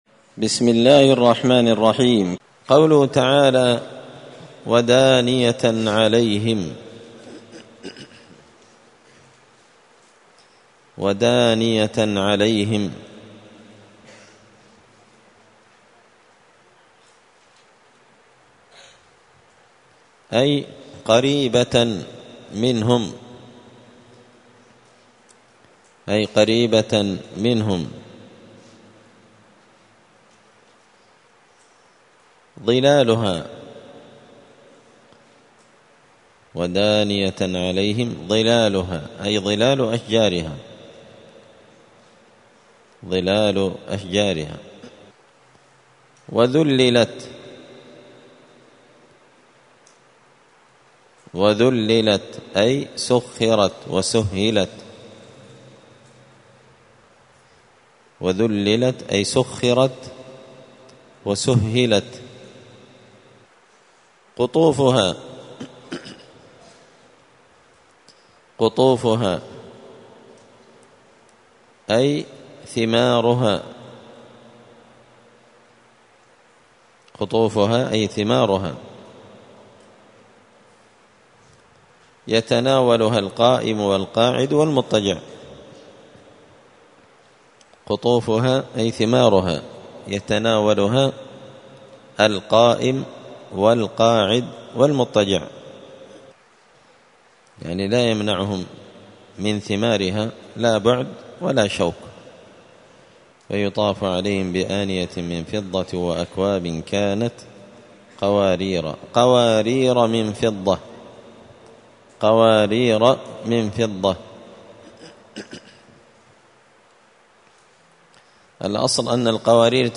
الأحد 23 ربيع الأول 1445 هــــ | الدروس، دروس القران وعلومة، زبدة الأقوال في غريب كلام المتعال | شارك بتعليقك | 77 المشاهدات
مسجد الفرقان قشن_المهرة_اليمن